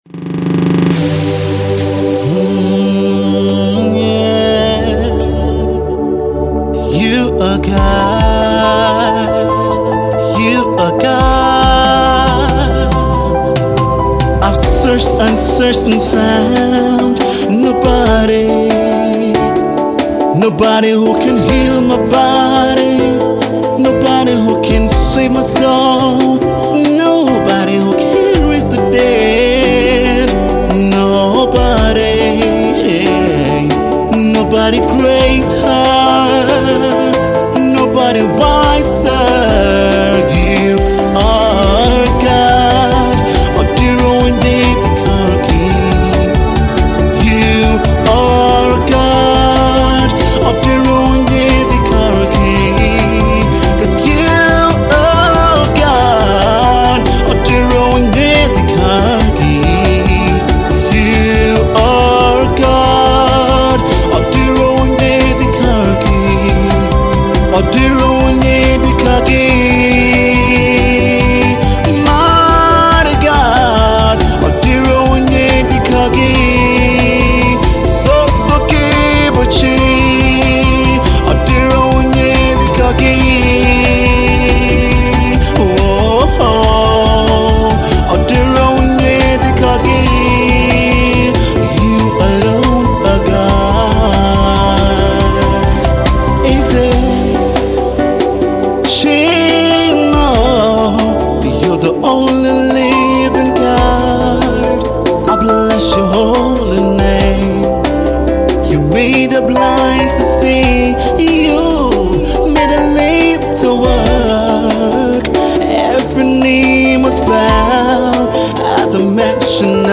rock song